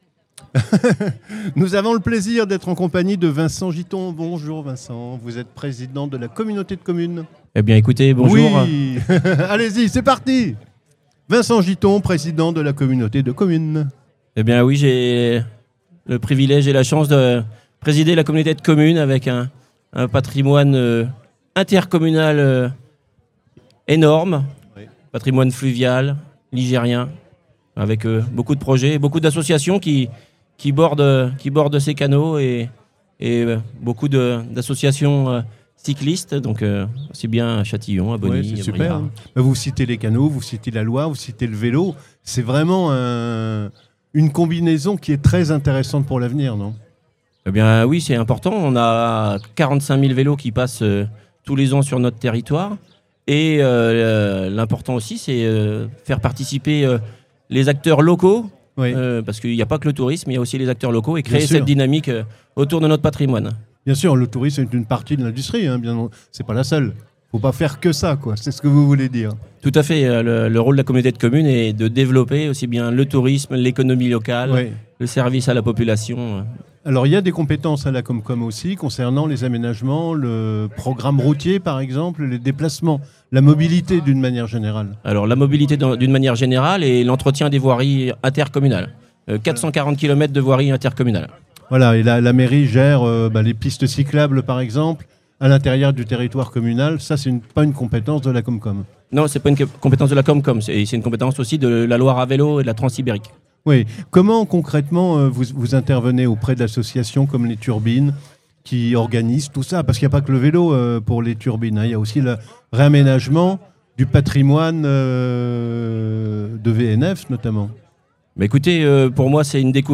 À l’occasion du Grand Répar Vélo des Turbines, Studio 45 vous propose une série d’interviews réalisées en direct du Pont Canal de Briare.